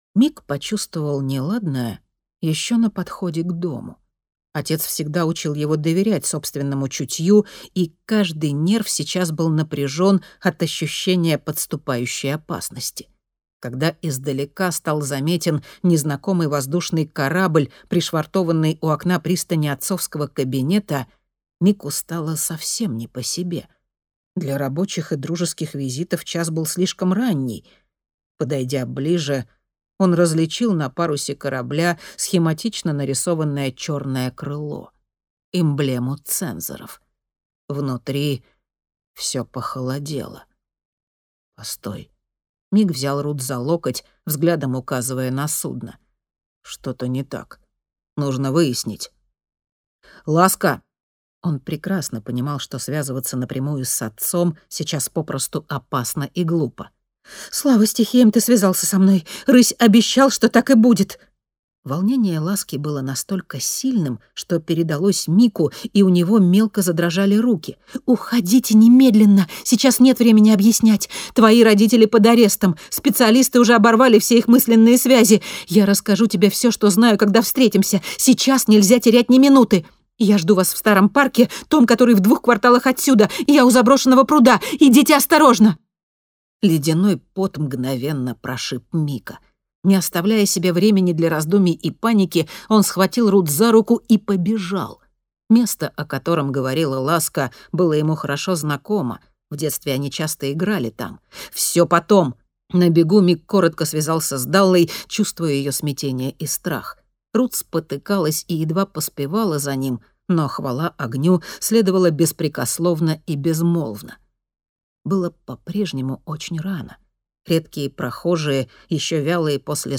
Аудиокнига Рубеж Стихий. Забытая правда | Библиотека аудиокниг